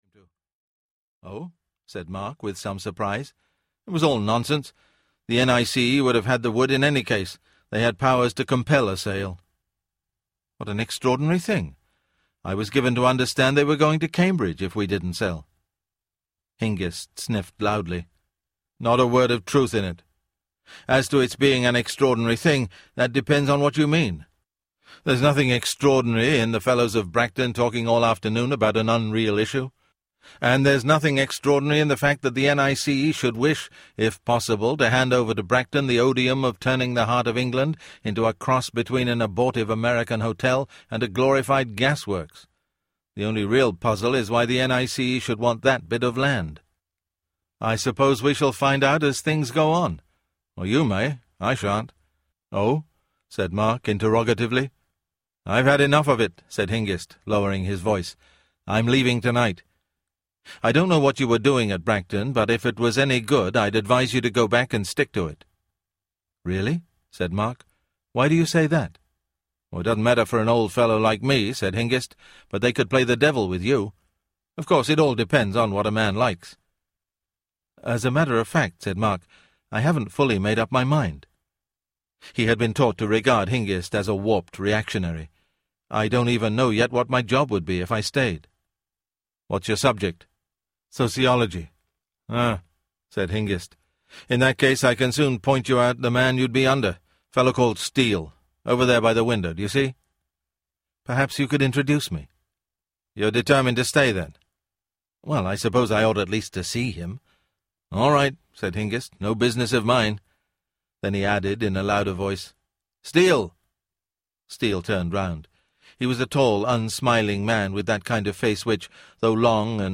That Hideous Strength (The Space Trilogy, Book #3) Audiobook